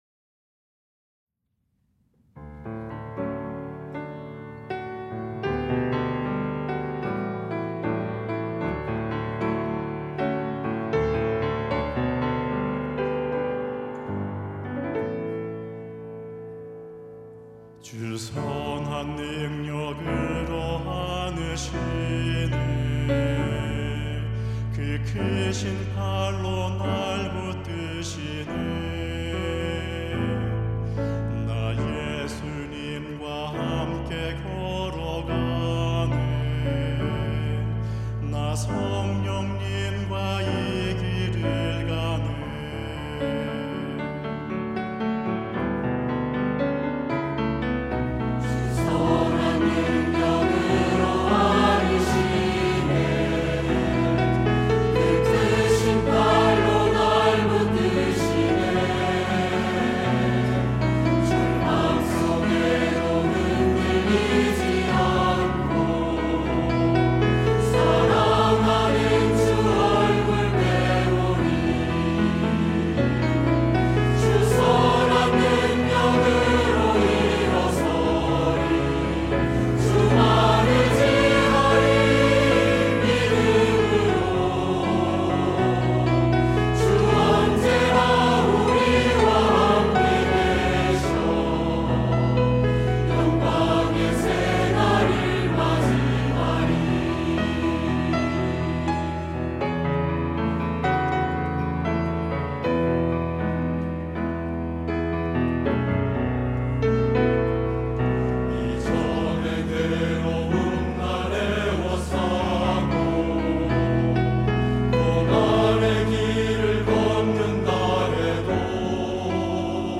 할렐루야(주일2부) - 선한 능력으로
찬양대